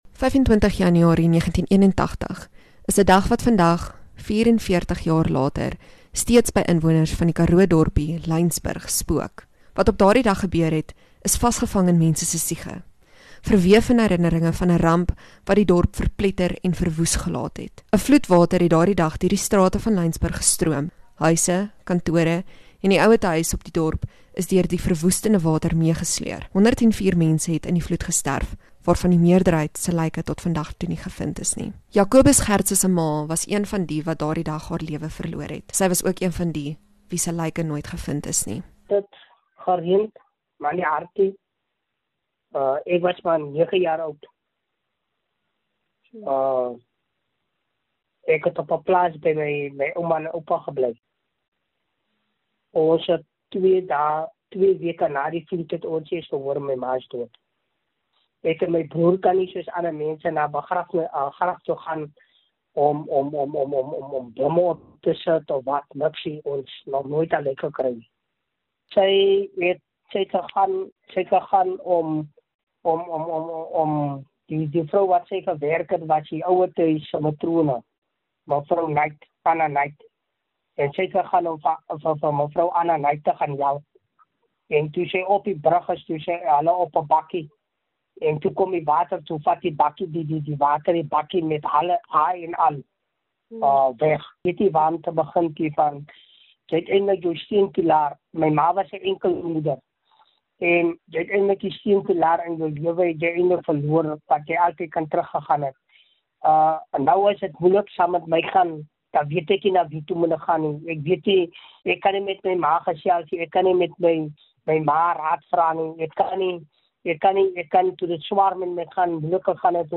Maroela Media gesels met interessante mense in die ateljee.